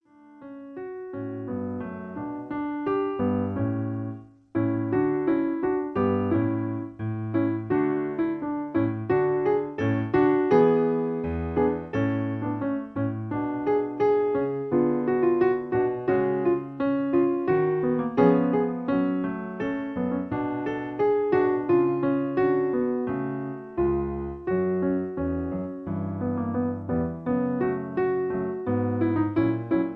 In D. Piano Accompaniment